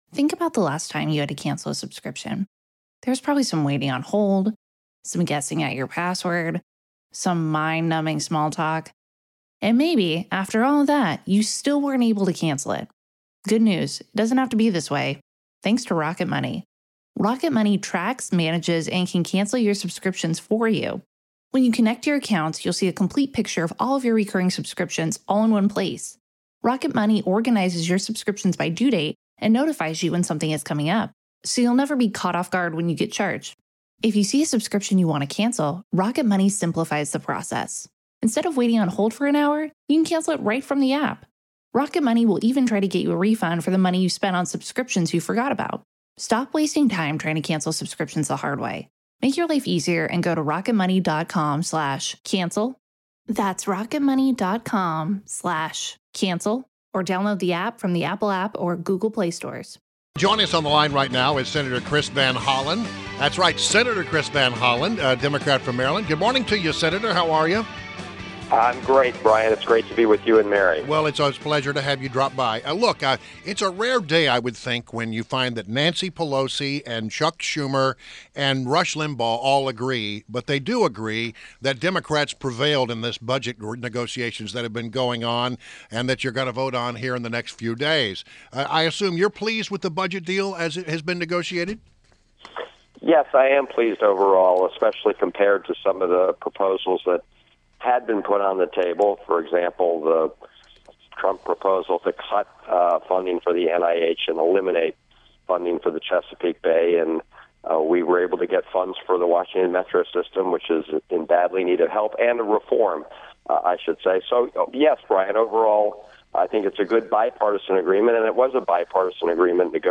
WMAL Interview - SENATOR CHRIS VAN HOLLEN - 05.03.17